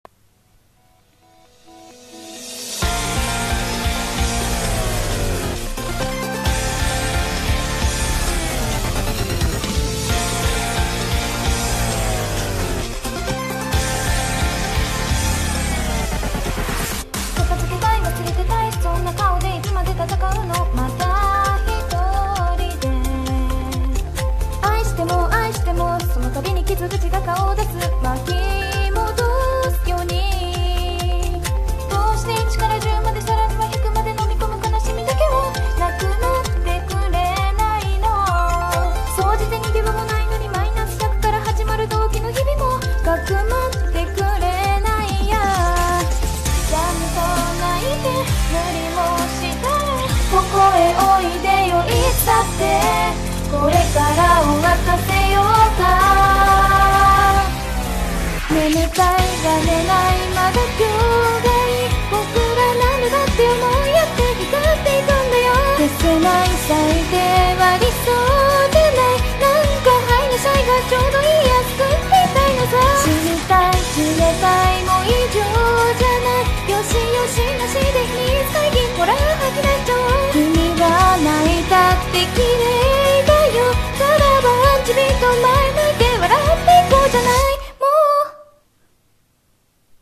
【二人声劇】未来繫フロントライン